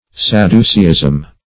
Search Result for " sadduceeism" : The Collaborative International Dictionary of English v.0.48: Sadduceeism \Sad"du*cee`ism\, Sadducism \Sad"du*cism\, n. The tenets of the Sadducees.
sadduceeism.mp3